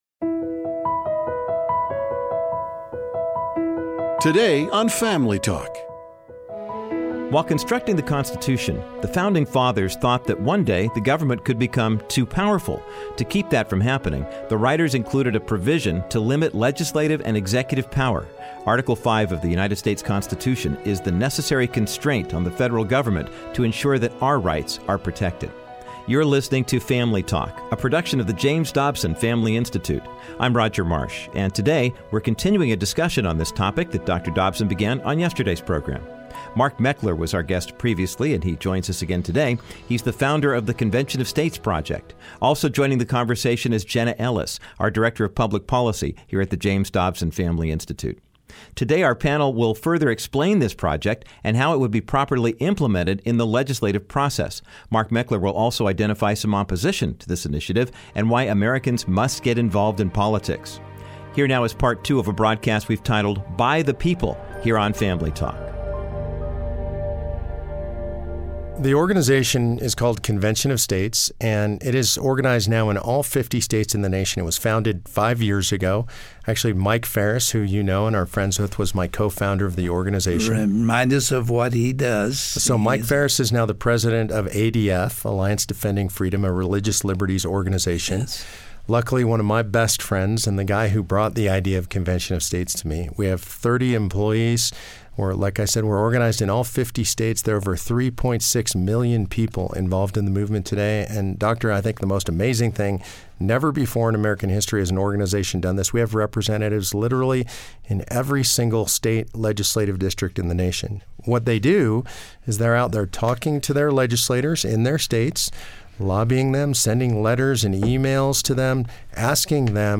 Dr. Dobson and Public Policy Director Jenna Ellis, continue their conversation with Mark Meckler. Mark explains why he started the Convention of States project and concludes by discussing how ordinary citizens can get involved in politics.